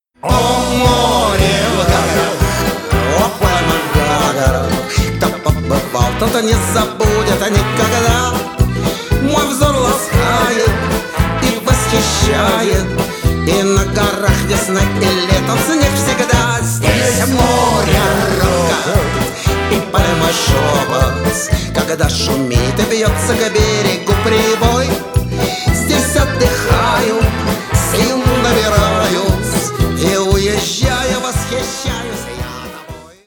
Шансон
весёлые